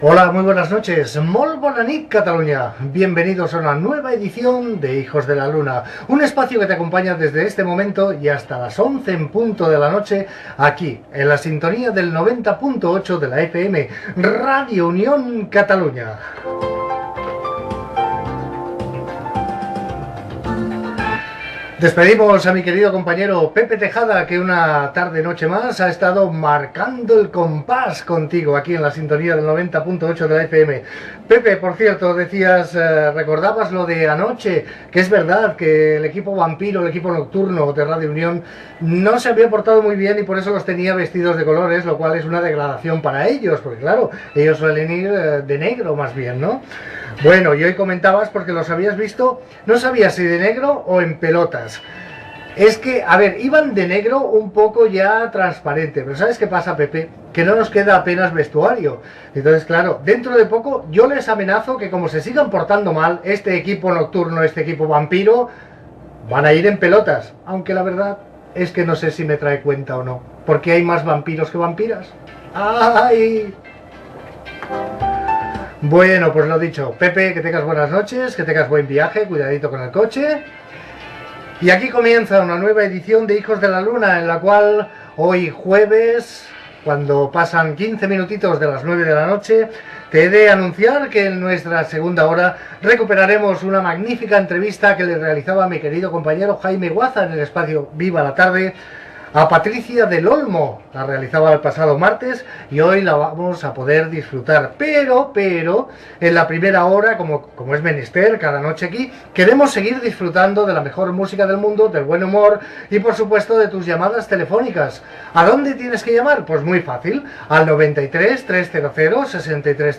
Presentació amb identificació de la ràdio, hora, sumari, telèfons de participació
Entreteniment